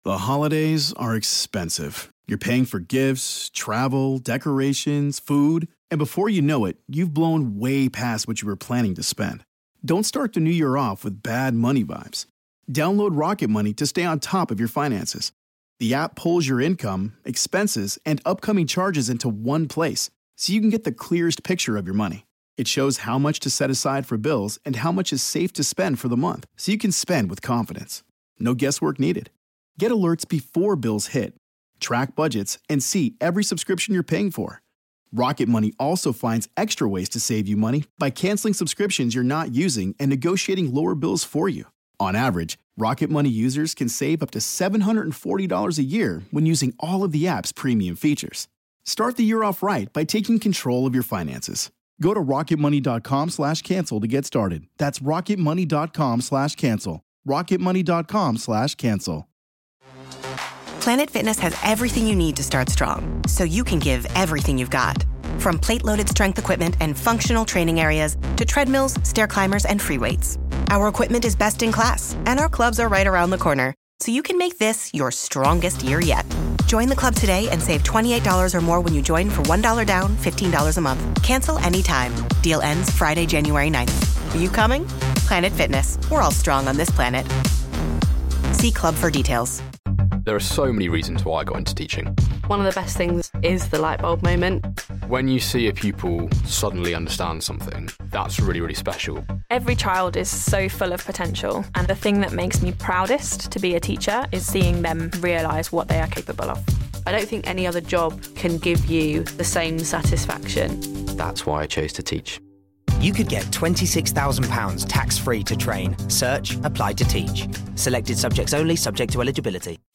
Microsoft says this is the last console generation for Xbox, Roundtable discussion about Titanfall 2 and Battlefield 1.